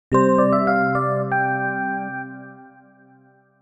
Примеры звуковых логотипов
Музыкальный логотип